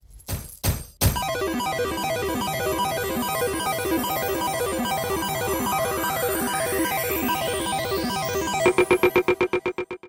winn_siren.wav